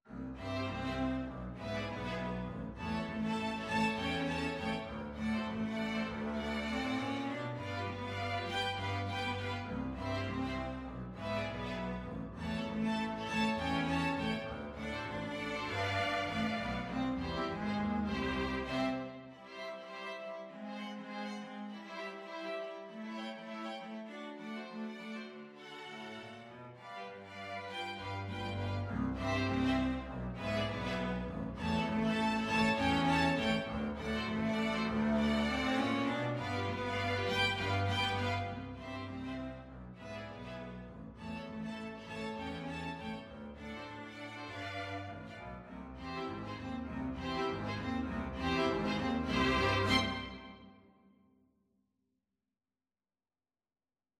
Violin 1Violin 2ViolaCelloDouble Bass
Quick Swing = c. 100
2/2 (View more 2/2 Music)
Jazz (View more Jazz String Ensemble Music)